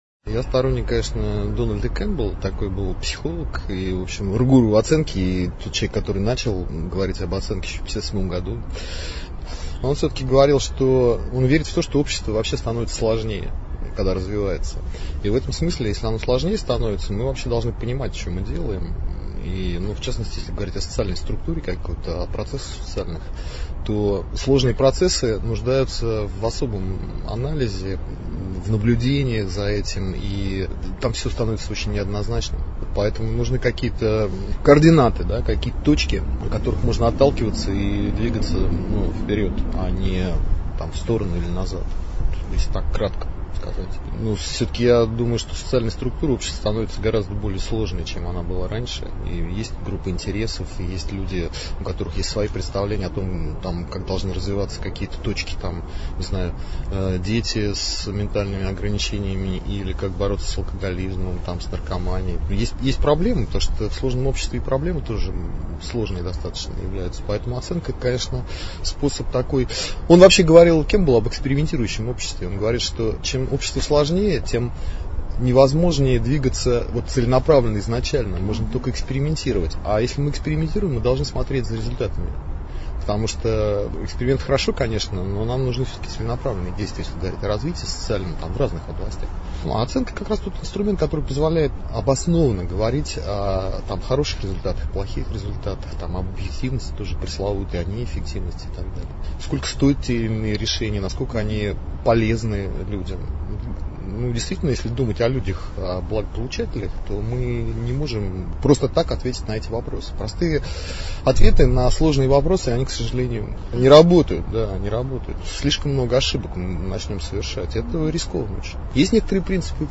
Интервью
«Расскажем» — аудиопроект Агентства социальной информации: живые комментарии экспертов некоммерческого сектора на актуальные темы.